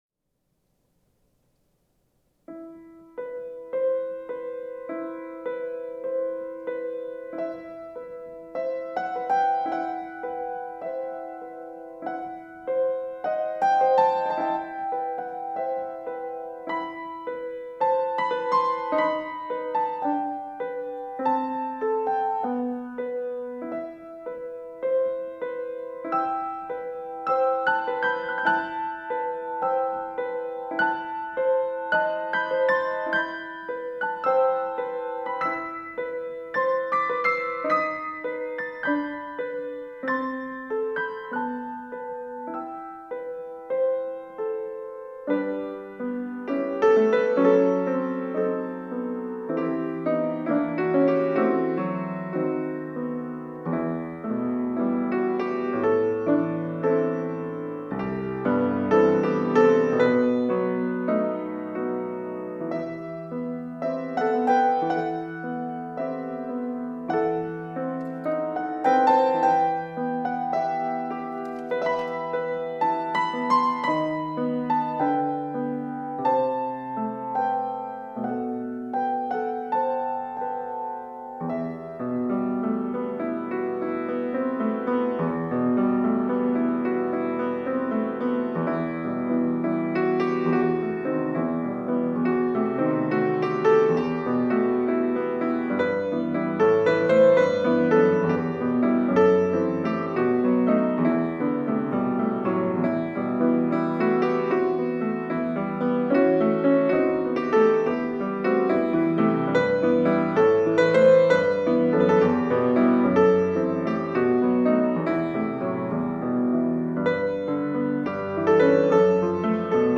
특송과 특주 - 어느 민족 누구에게나